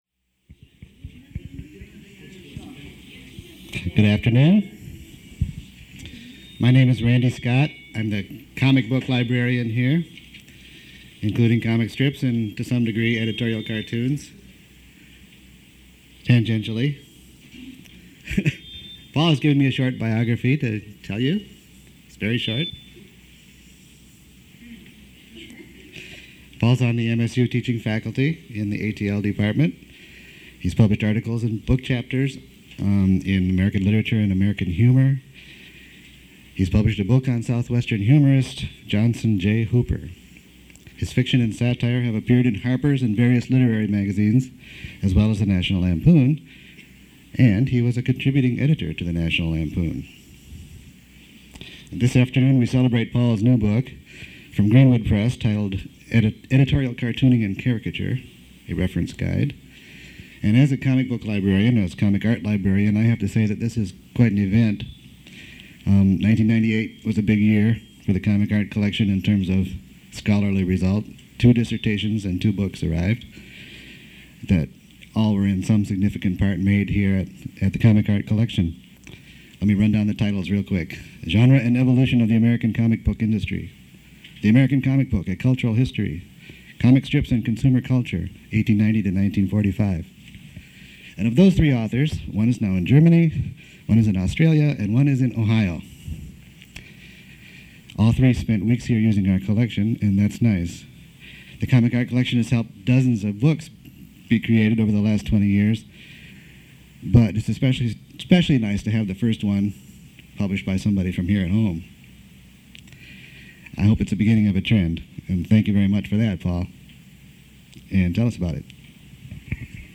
Recording ends abruptly.
Part of the Michigan State University Libraries' Colloquia Series. Held at the MSU Main Library.